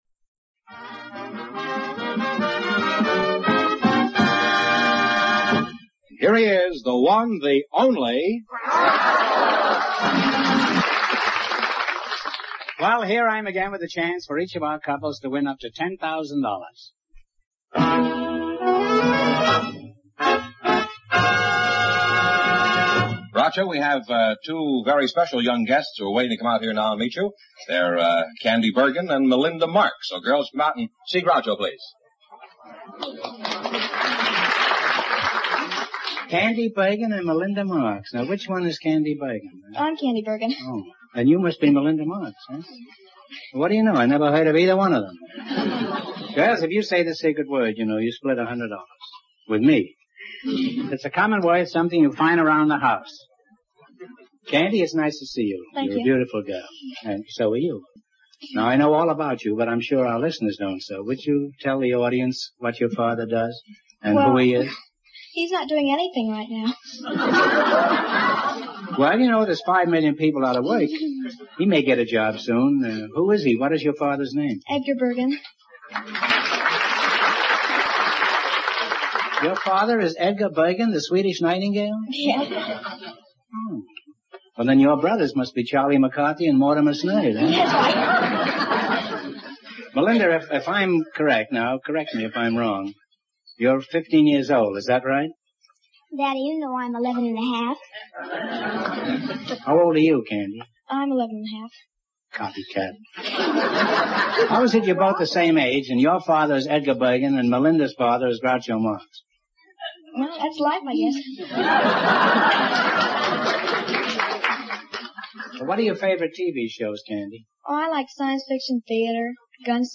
Imagine giving those quiz questions from the audio clip, to 6th grade students today.